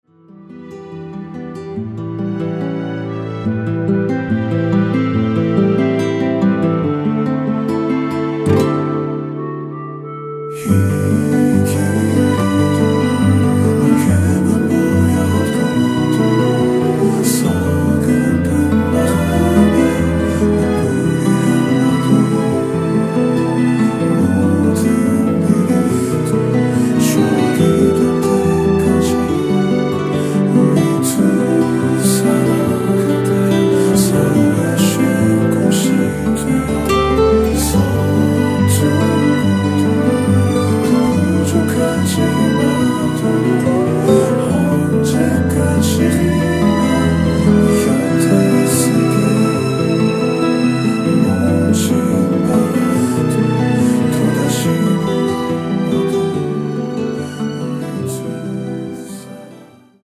원키에서(-2)내린 멜로디와 코러스 포함된 MR입니다.(미리듣기 확인)
앞부분30초, 뒷부분30초씩 편집해서 올려 드리고 있습니다.
중간에 음이 끈어지고 다시 나오는 이유는